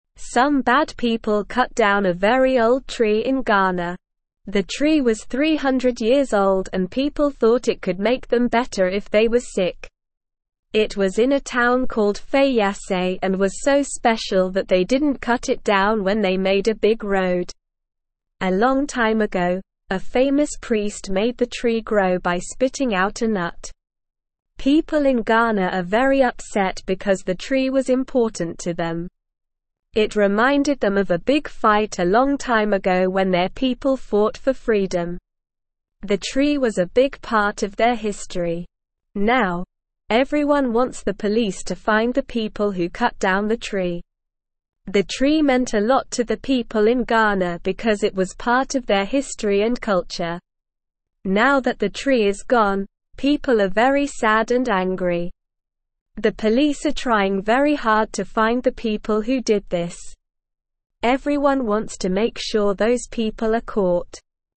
Slow
English-Newsroom-Lower-Intermediate-SLOW-Reading-Old-Tree-in-Ghana-Cut-Down-by-Bad-People.mp3